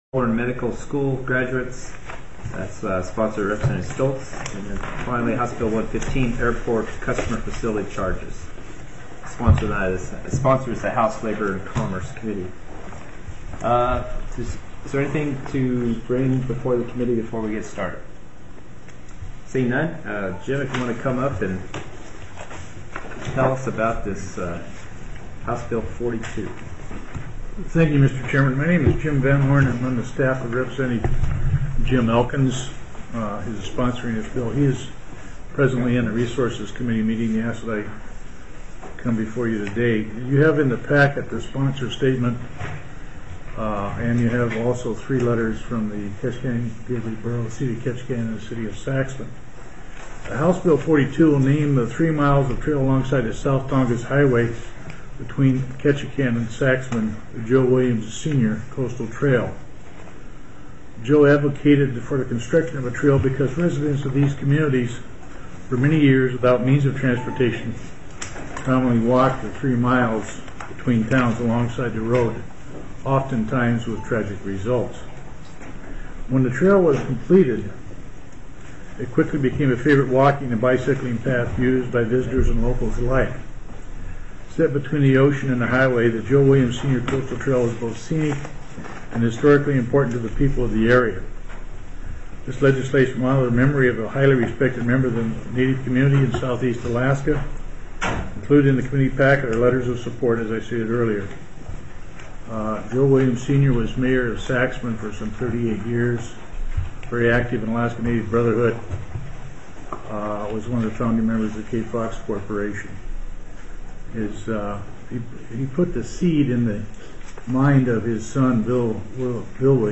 + teleconferenced